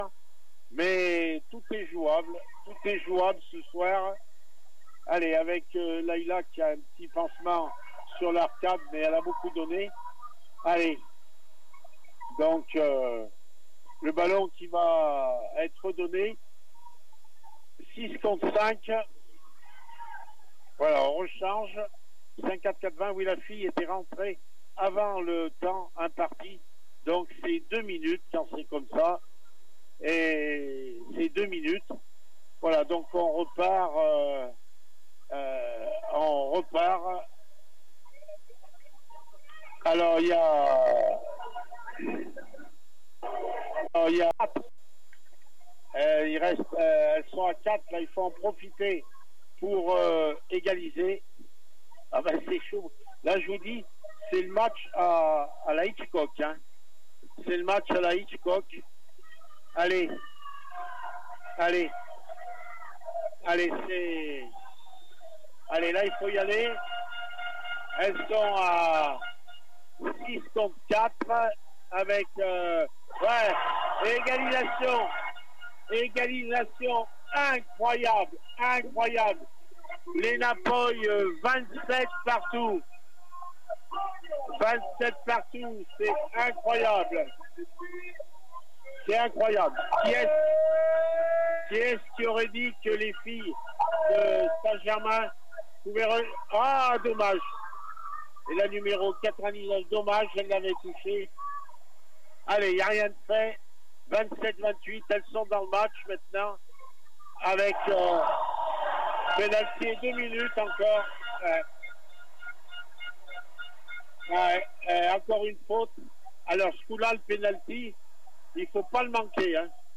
extrait du match